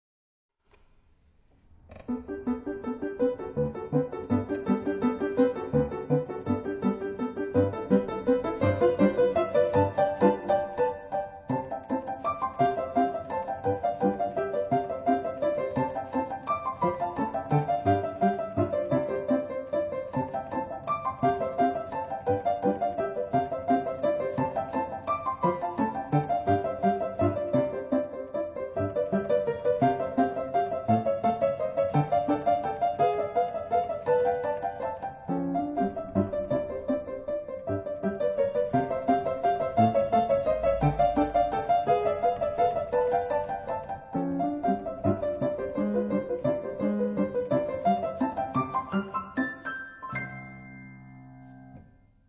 ピアノコンサート２
自宅のGPによる演奏録音 　　 デジピによる演奏録音